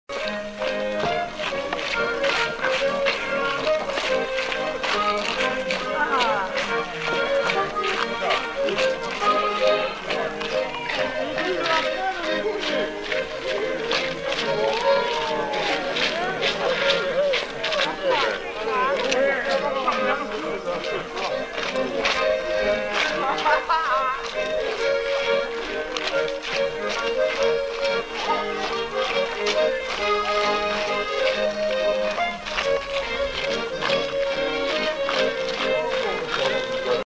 Mazurka
Aire culturelle : Savès
Lieu : Espaon
Genre : morceau instrumental
Instrument de musique : accordéon diatonique ; violon
Danse : mazurka
Notes consultables : Coupure avant la fin du morceau.